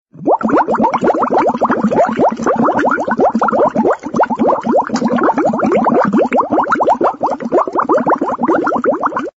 Blasen klingelton kostenlos
Kategorien: Soundeffekte
Blasen.mp3